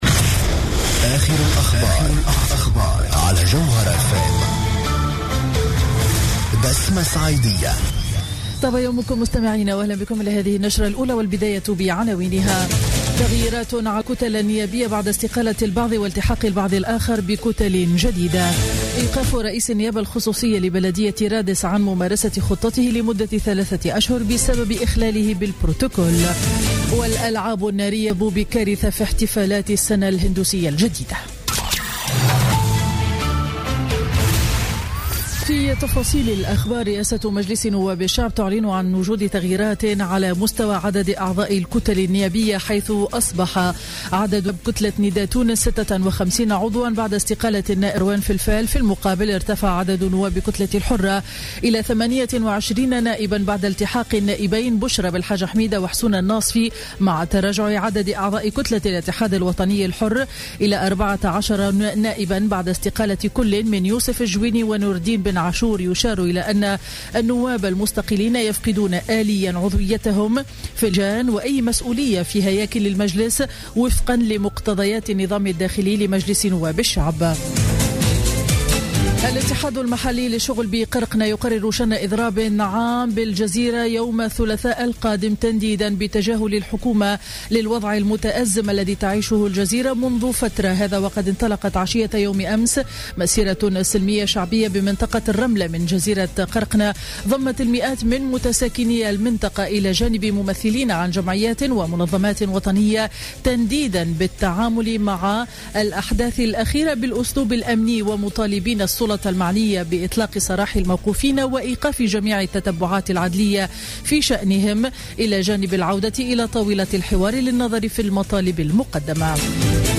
نشرة أخبار السابعة صباحا ليوم الأحد 10 أفريل 2016